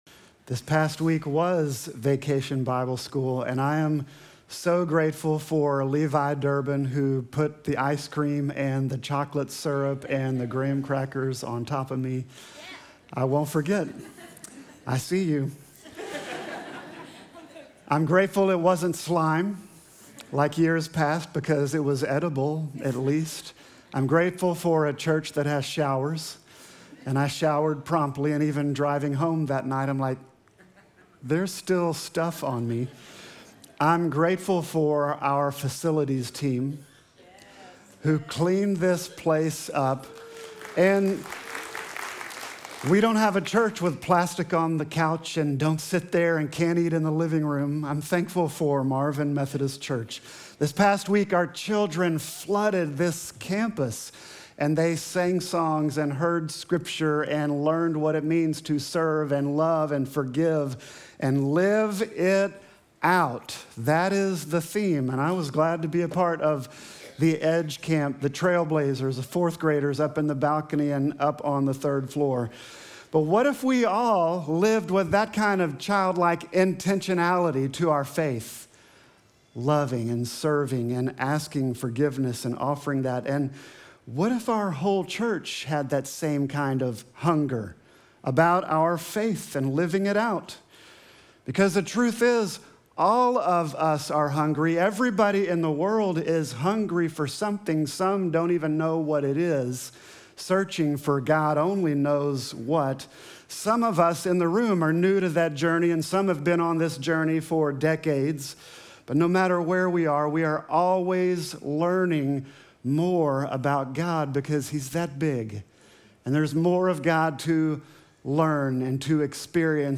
Sermon text: John 10:10